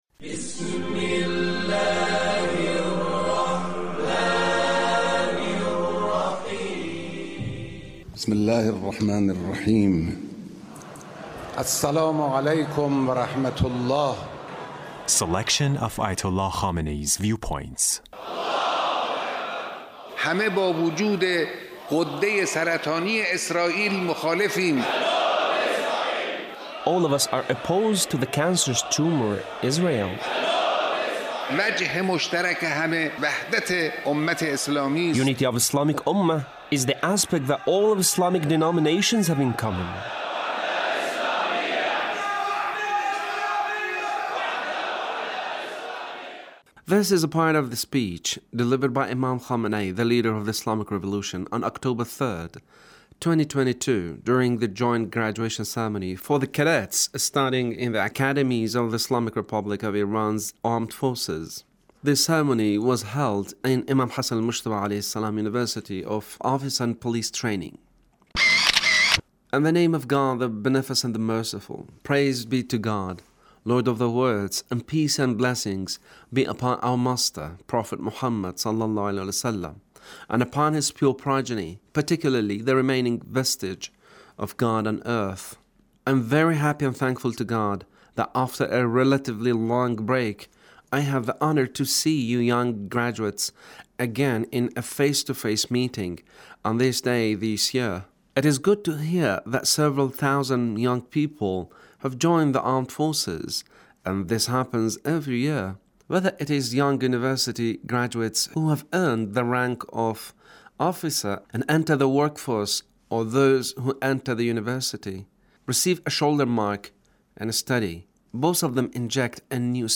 Leader's Speech on Graduation ceremony of Imam Hassan Mojtaba University